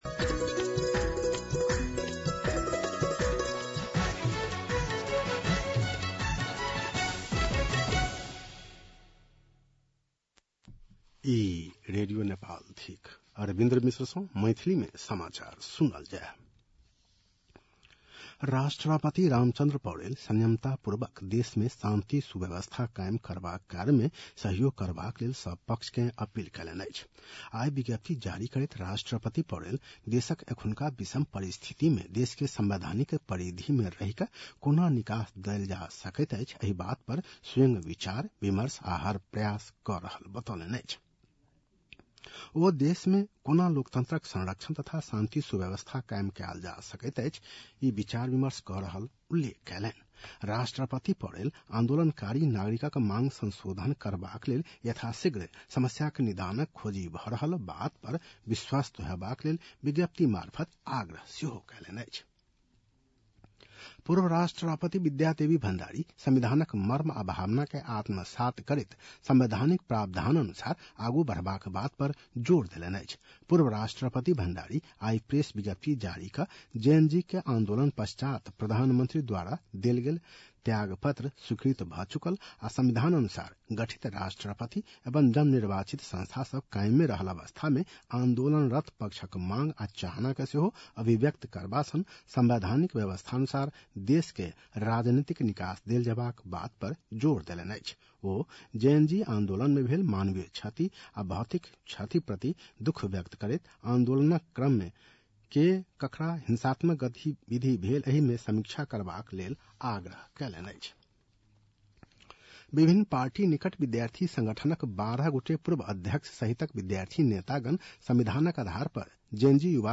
An online outlet of Nepal's national radio broadcaster
मैथिली भाषामा समाचार : २६ भदौ , २०८२